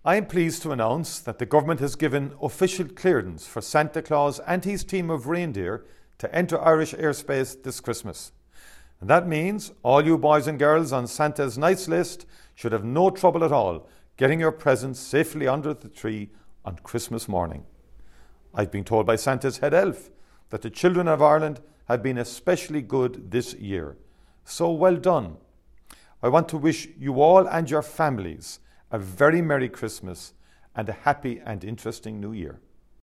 Foreign Affairs Minister Micheál Martin had this to say after those discussions: